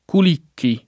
[ kul & kki ]